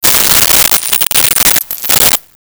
Coins Thrown 03
Coins Thrown 03.wav